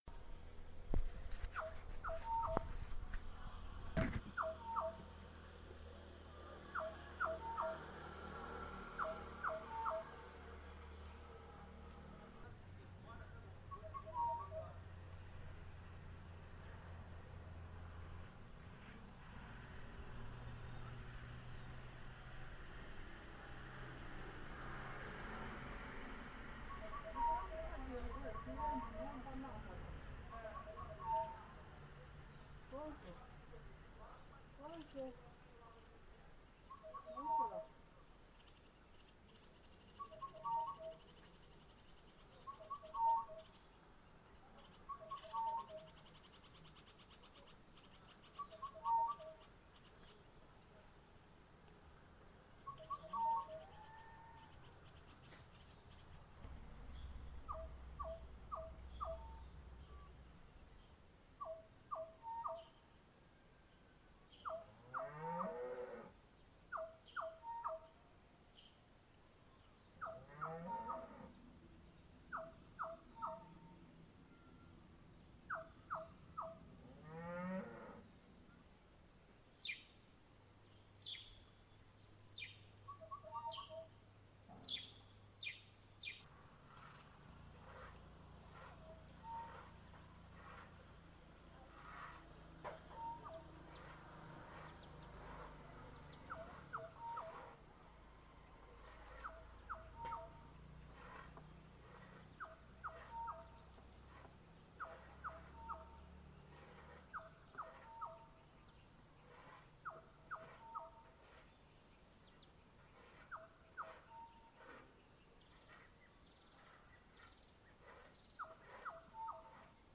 Rebero-Kigali birds morning 21st June 2015
05_birds24june.mp3